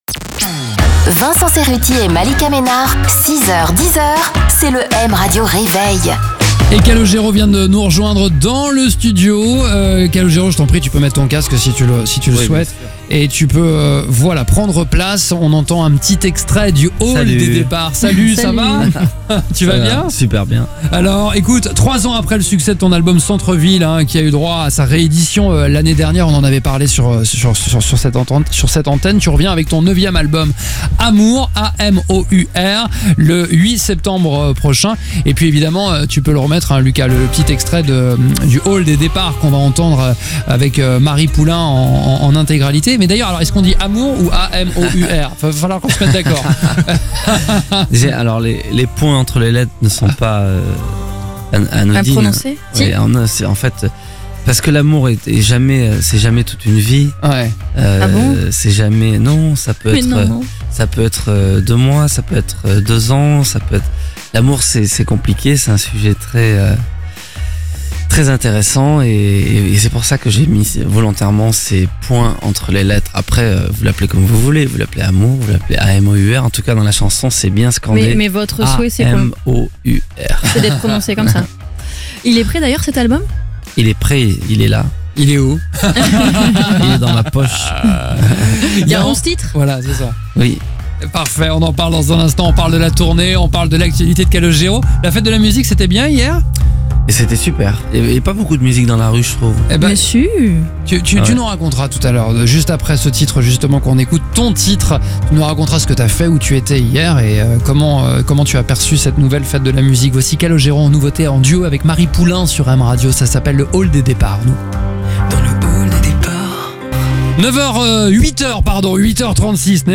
Jeudi 22 juin, Calogero était l’invité du M Radio Réveil de Vincent Cerutti et Malika Ménard sur M Radio !